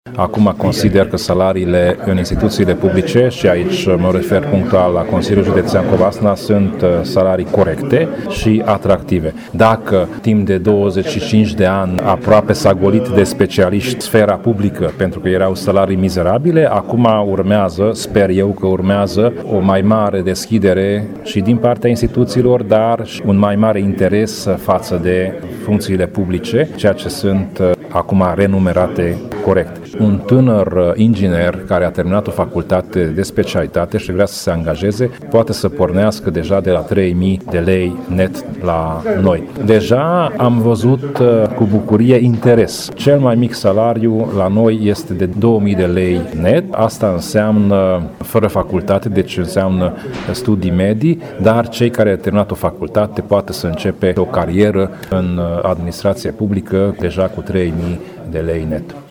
Preşedintele Consiliului Judeţean Covasna, Tamas Sandor a declarat că salariile din administraţia judeţeană au devenit „corecte şi atractive”: